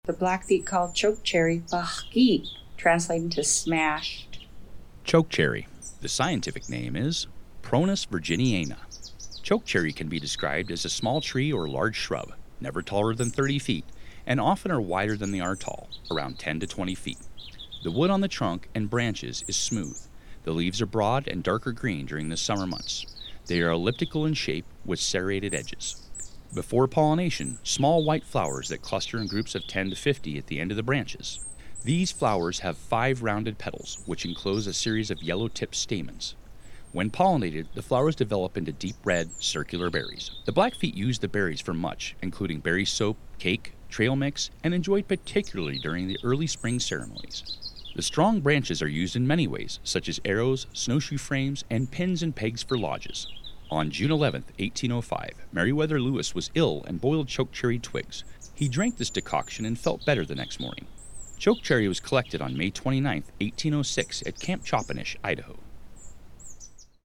Narration: